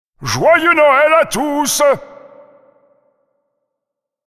Ensemble voix Joyeux noël